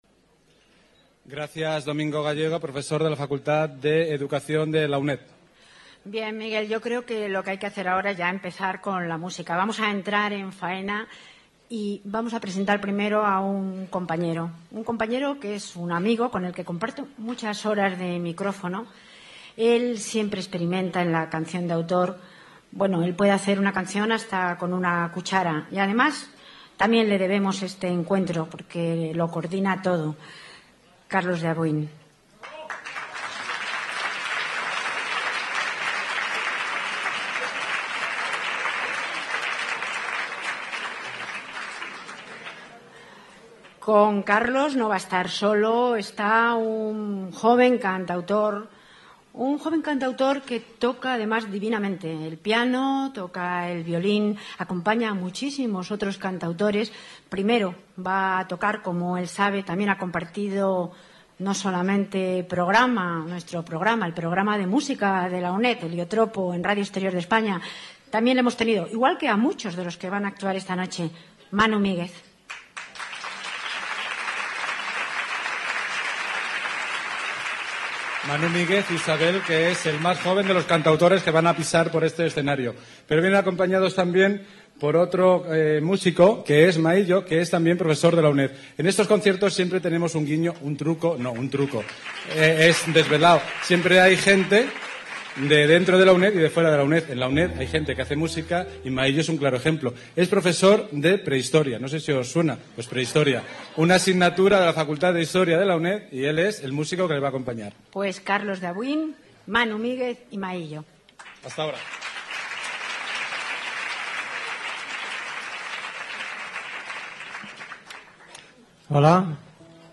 Noche de Canción de autor